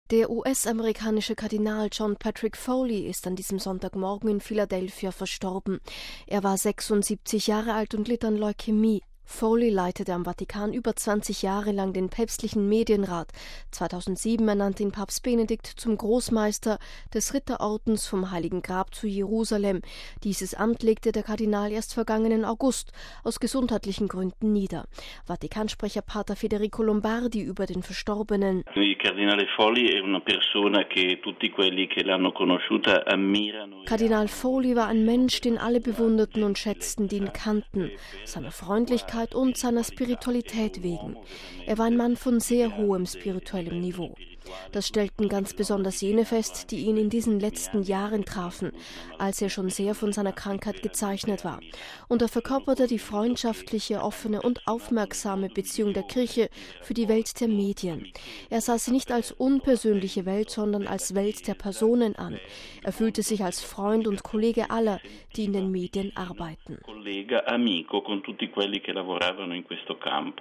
Vatikansprecher P. Federico Lombardi über den Verstorbenen: